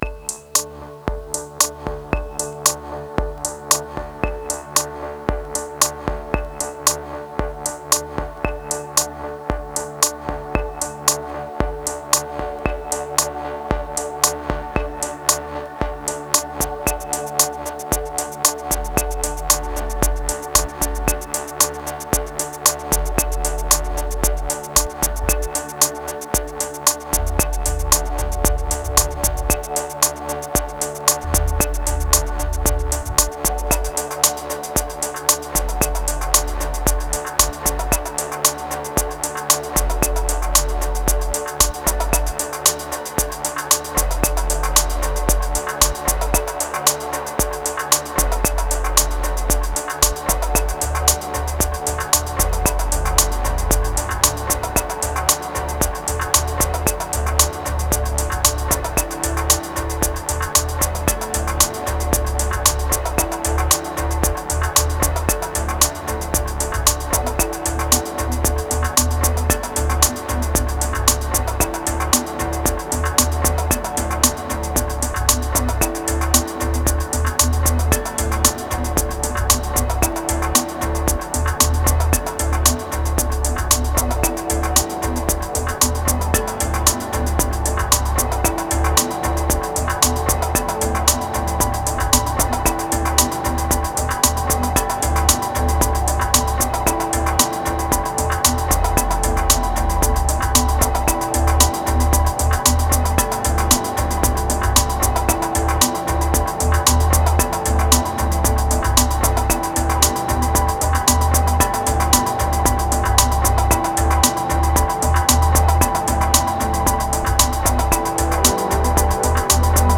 1259📈 - 88%🤔 - 57BPM🔊 - 2024-05-16📅 - 520🌟